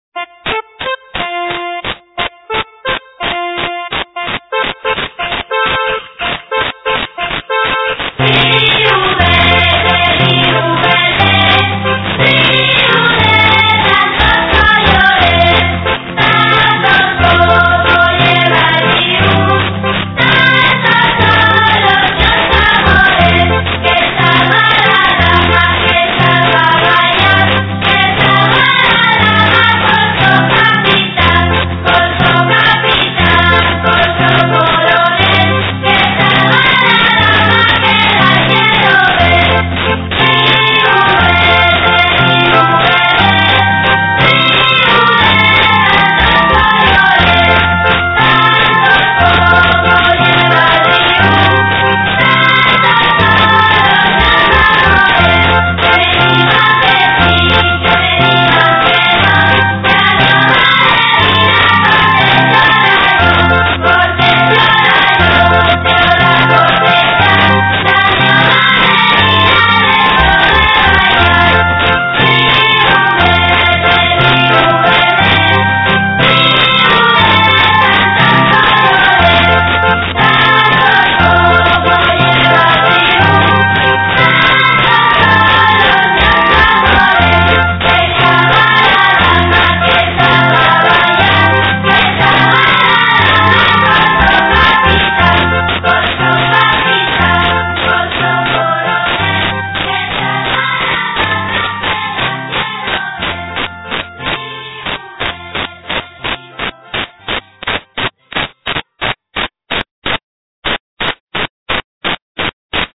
Un cantar ya un baille
Cantares vieyos voces nueves